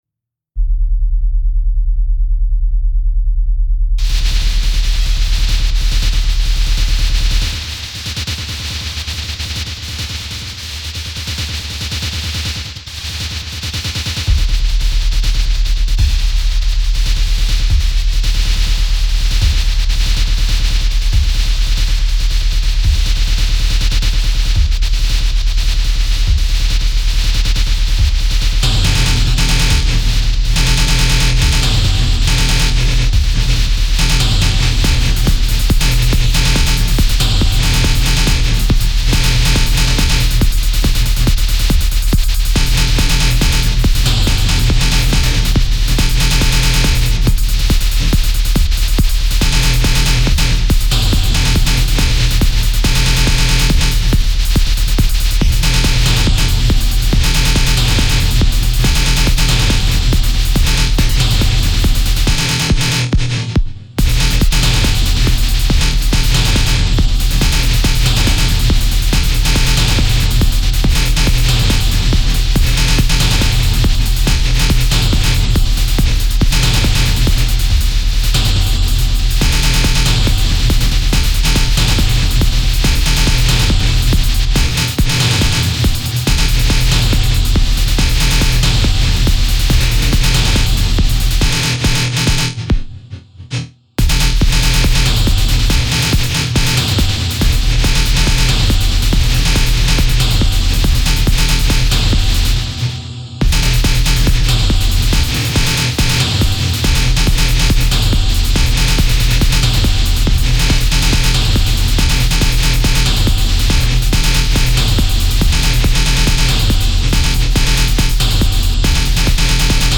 Music / Techno